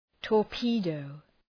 Προφορά
{tɔ:r’pi:dəʋ}